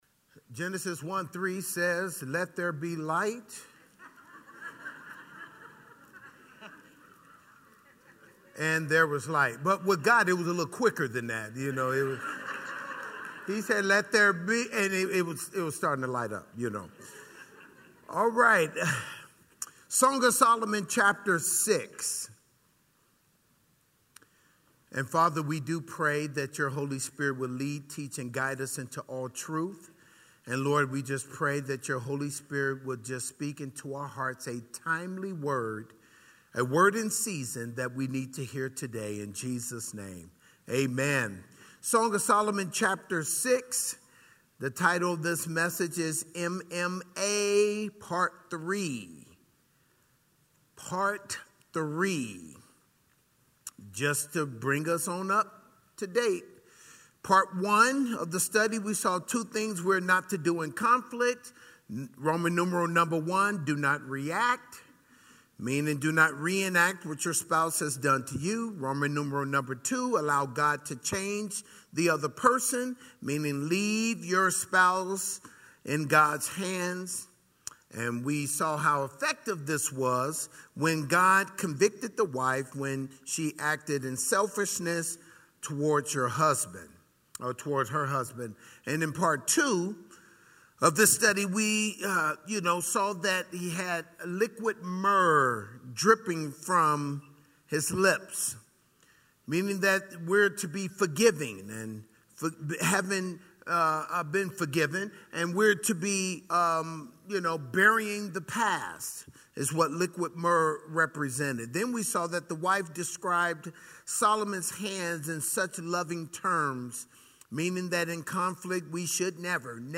The Vertical Marriage Conference 2018